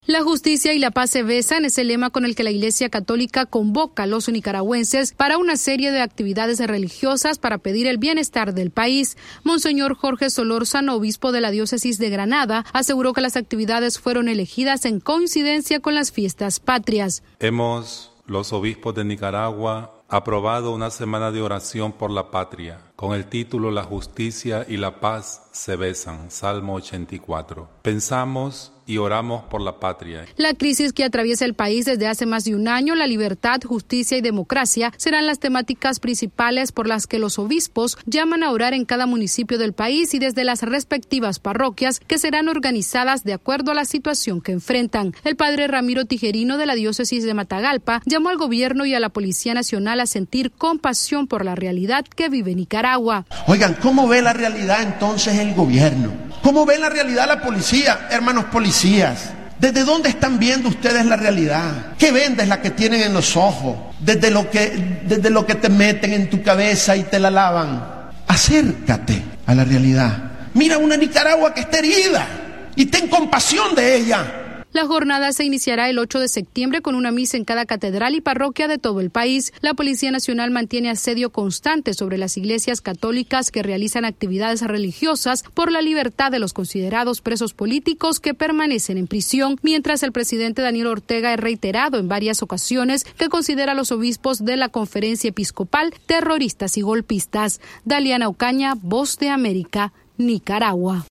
VOA: Informe desde Nicaragua